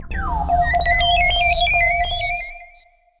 alien_beep_boop.ogg